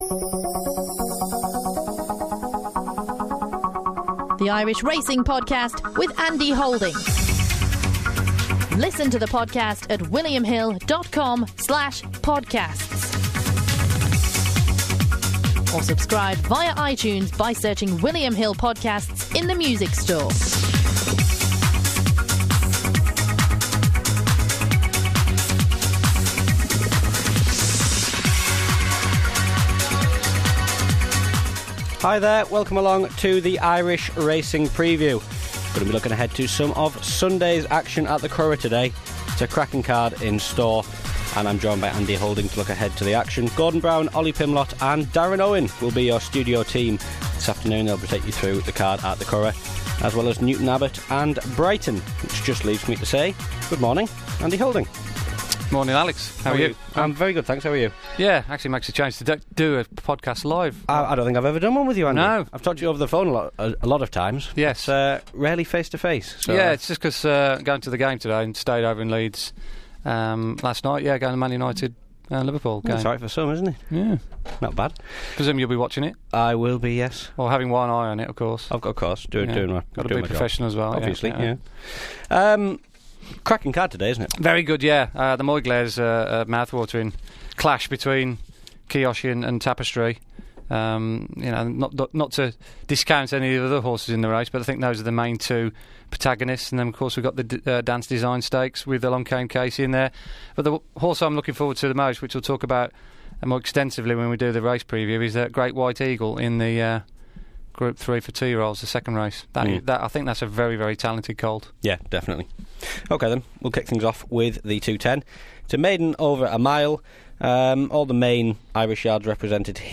makes a rare foray into the studio to discuss the meeting